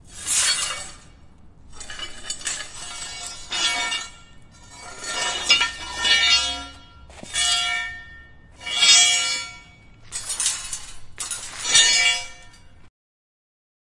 废弃的工厂金属后世界末日的回声" 拖动金属物体
描述：记录在爱尔兰都柏林的废弃工厂。使用Zoom H6和Rode NT4。
Tag: 空间 噪音 金属 工业 回声